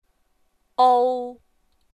舒声韵的示范发音为阴平调，入声韵则为阴入调。
ou.mp3